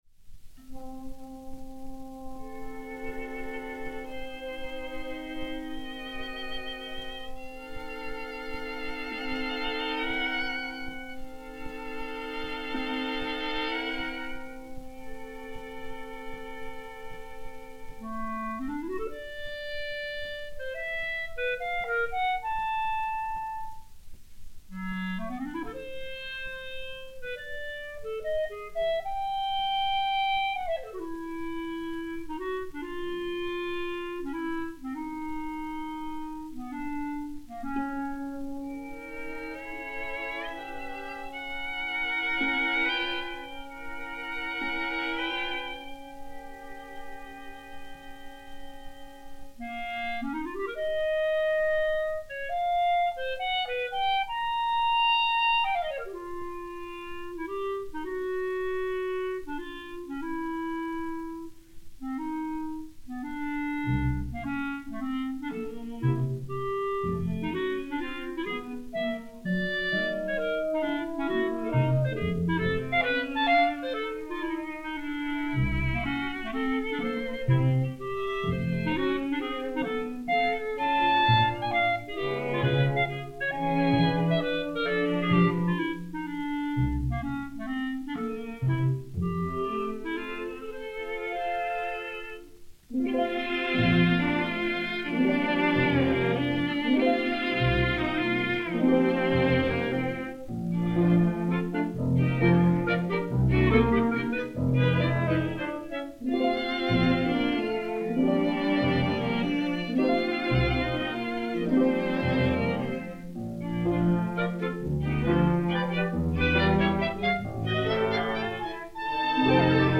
Divertissement chorégraphique